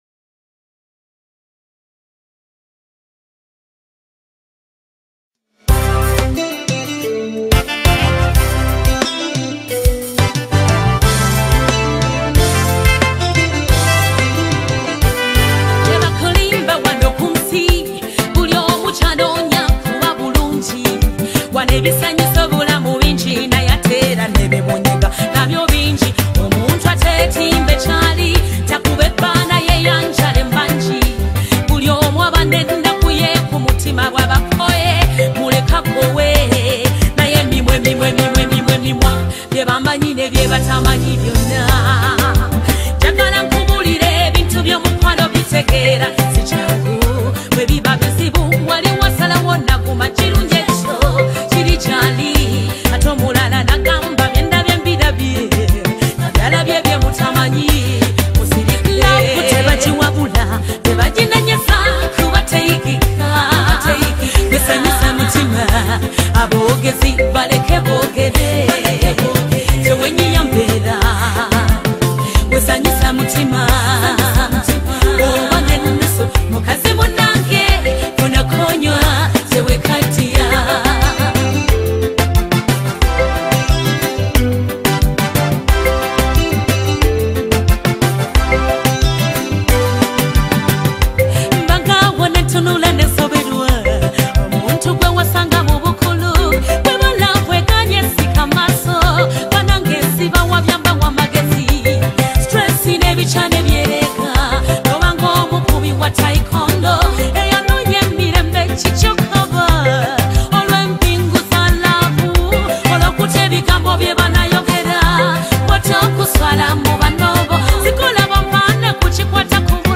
Genre: Oldies